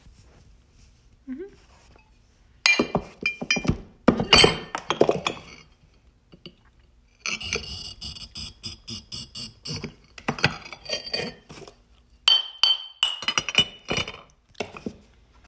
Glass Break 2